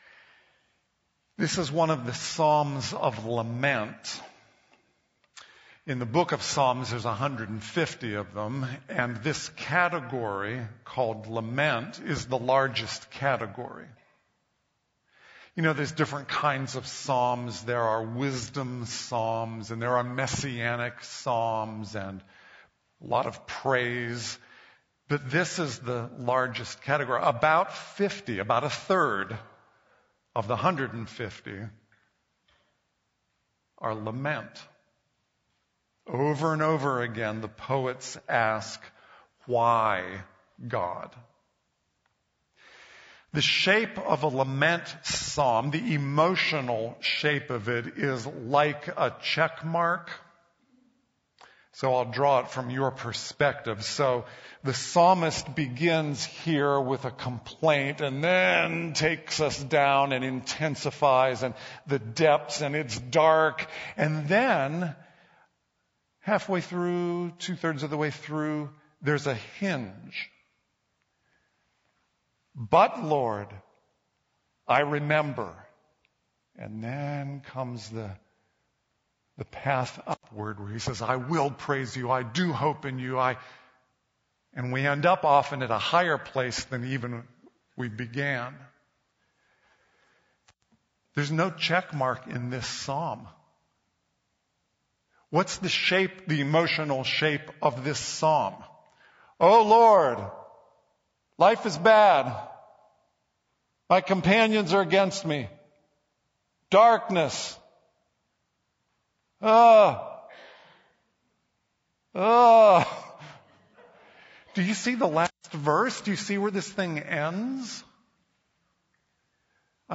@ the 4:35 minute mark of the original message)